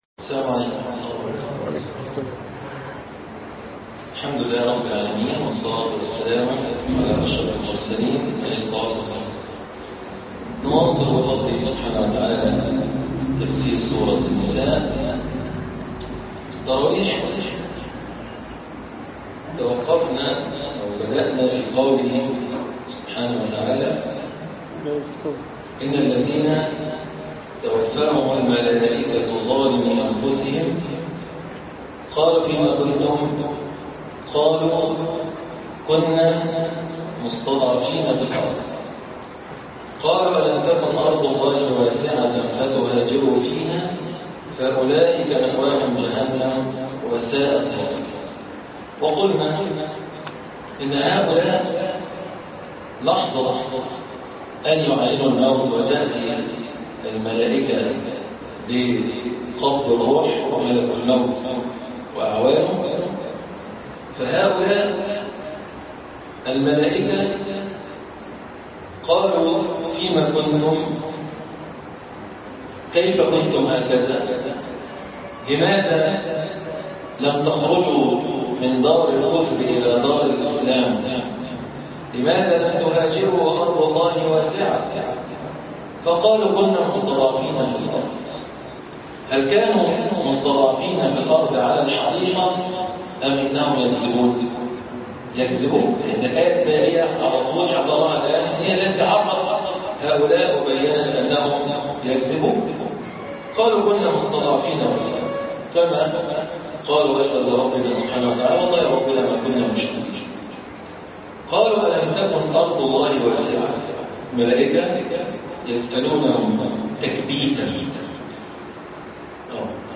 الدرس 39 الآيات